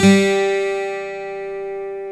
GTR 12 STR0L.wav